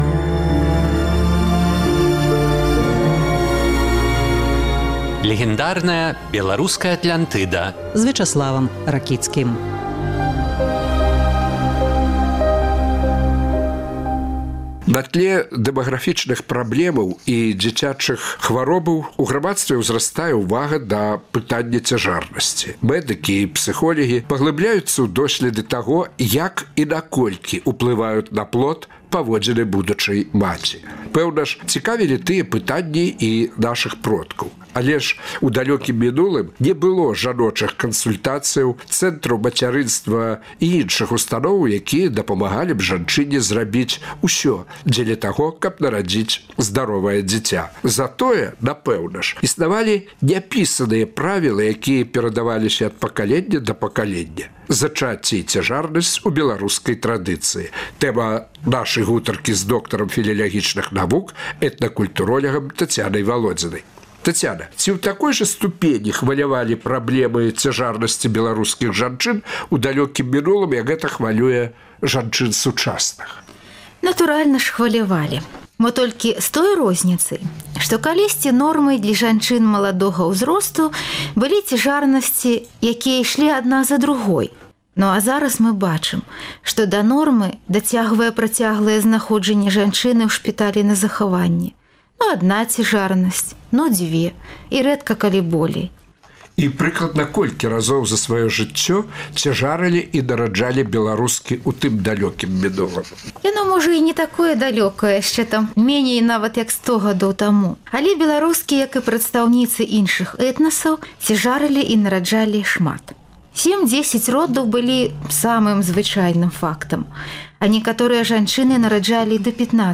Існаваў багаты комплекс няпісаных правілаў, як мусіла сябе паводзіць цяжарная жанчына. Якія яны, гэтыя правілы, і ці варта да іх прыслухоўвацца ў наш час? Гутарка